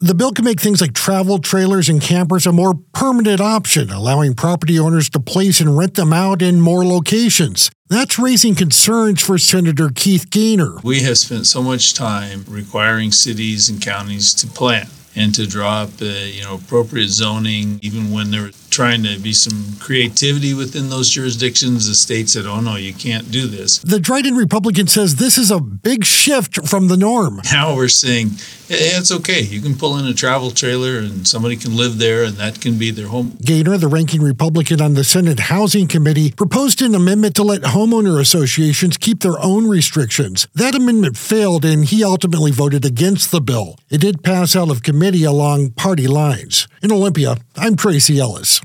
AUDIO: Goehner concerned about bill to allow more mobile dwelling units - Senate Republican Caucus